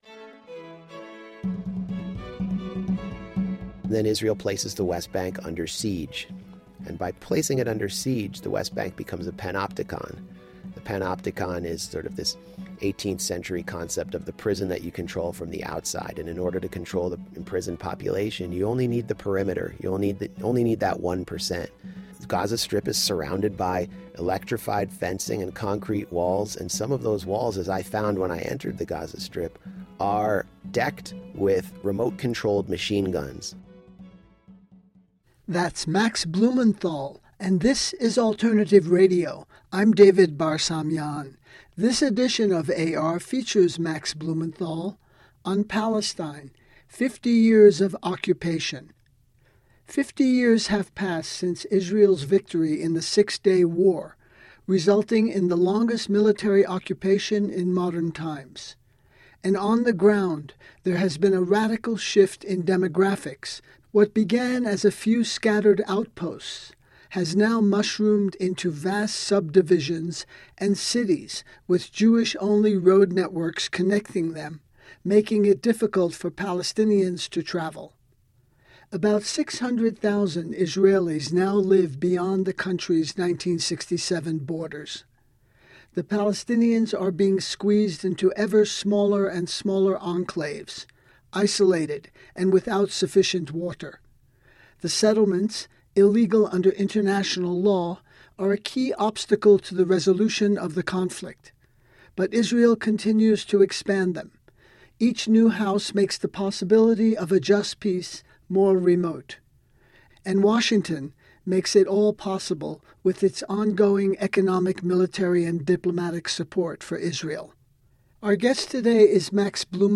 File Information Listen (h:mm:ss) 0:57:00 Max Blumenthal Palestine: 50 Years of Occupation Download (0) Blumenthal-Palestine.mp3 34,303k 80kbps Mono Comments: Boulder, CO Listen All